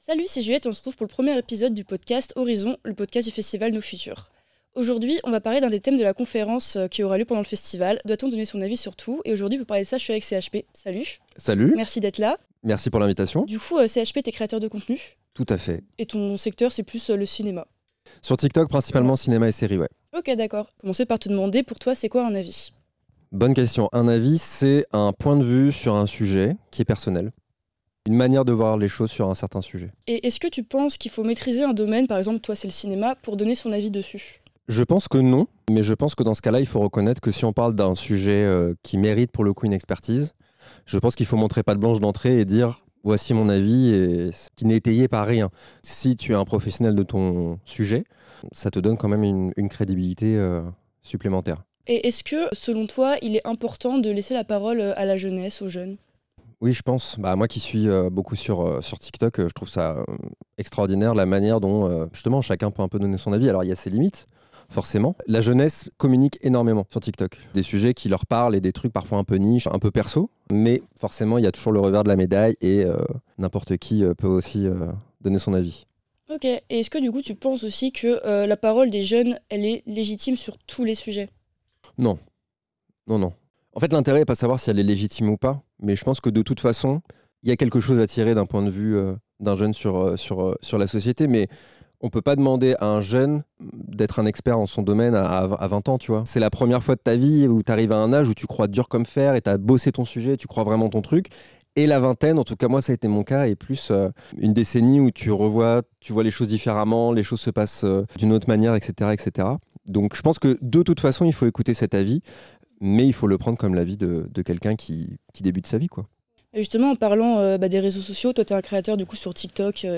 Ce premier épisode du podcast Horizon donne la parole au créateur de contenus